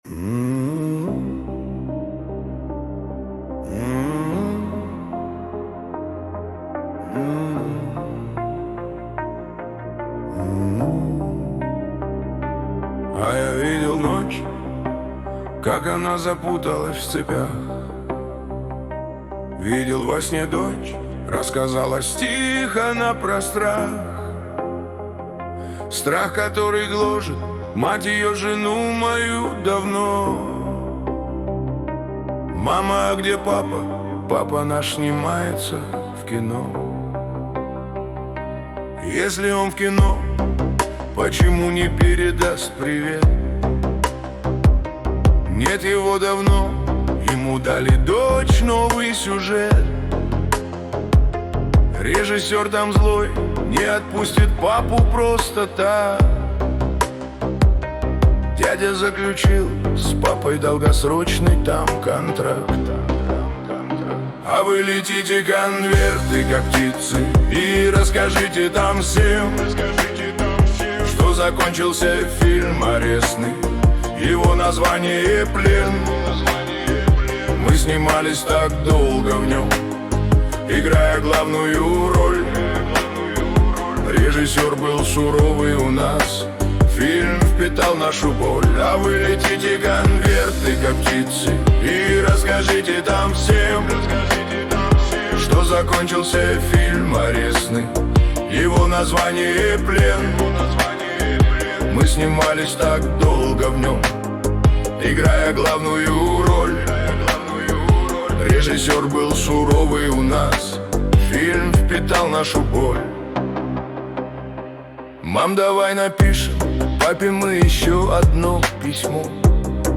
Качество: 236 kbps, stereo
Поп музыка, Русские поп песни